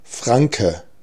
Ääntäminen
Synonyymit Francon Ääntäminen Tuntematon aksentti: IPA: /fʁɑ̃/ Haettu sana löytyi näillä lähdekielillä: ranska Käännös Konteksti Ääninäyte Substantiivit 1.